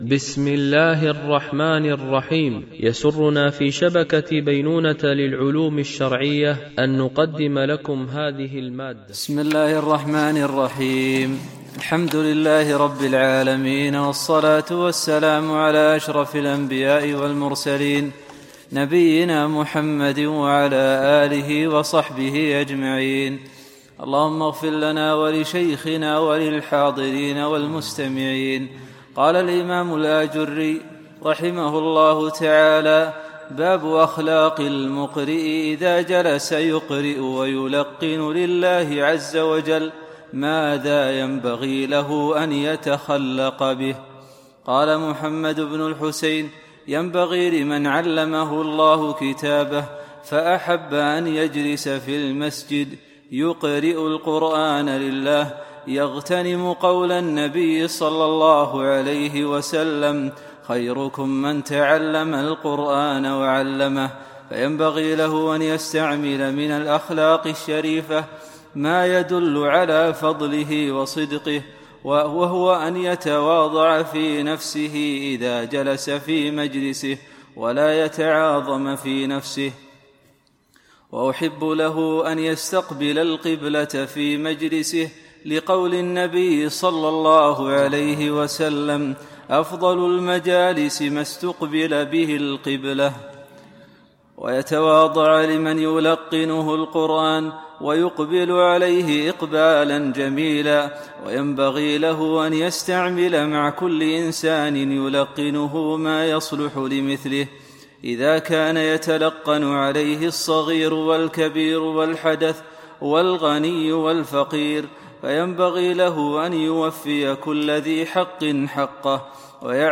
شرح آداب حملة القرآن ـ الدرس 7